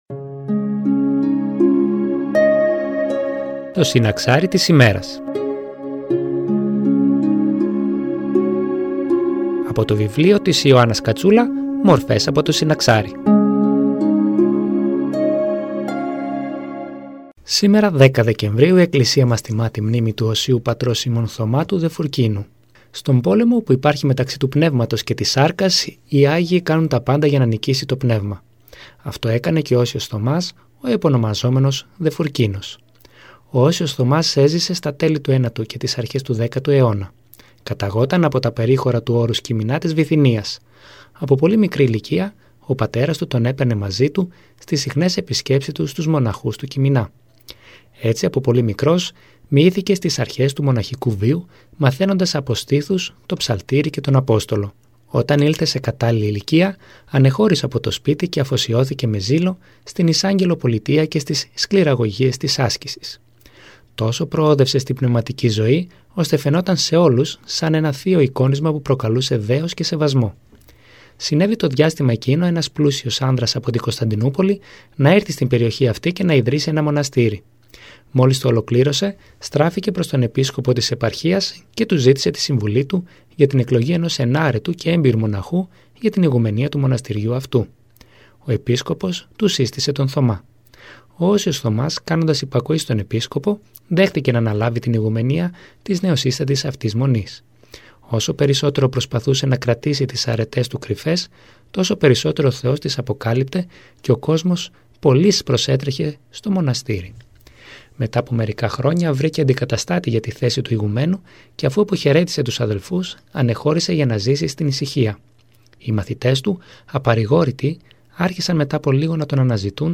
Εκκλησιαστική εκπομπή